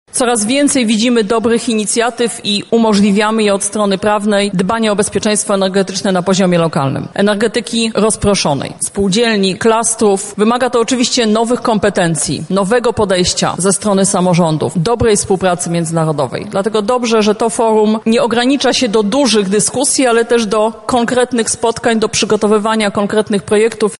• mówi Anna Moskwa, Ministra Klimatu i Środowiska.